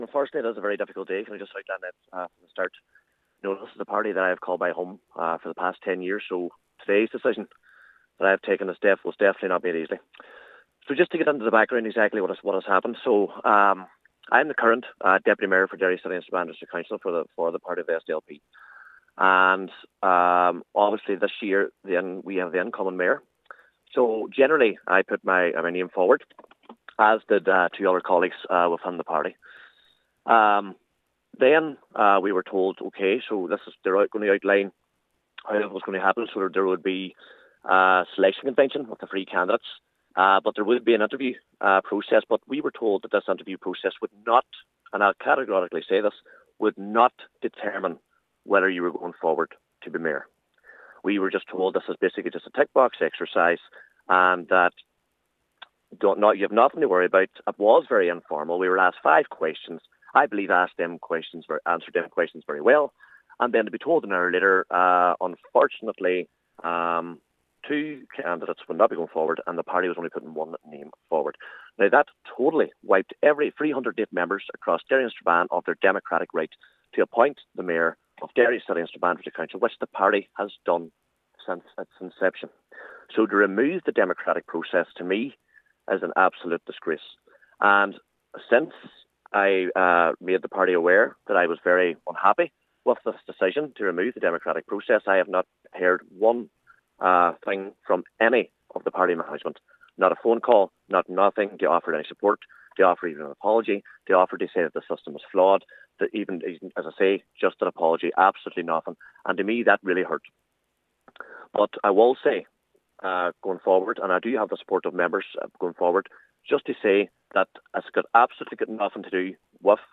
Cllr Barr says since he expressed his disappointment, he has been met with silence from the party: